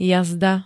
Zvukové nahrávky niektorých slov
dz7t-jazda.ogg